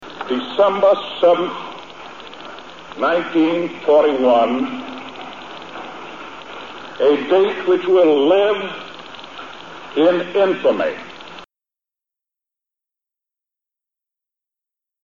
President Franklin Delano Roosevelt began this speech to Congress on December 8, 1941.